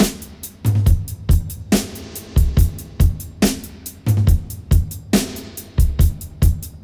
141-FX-05.wav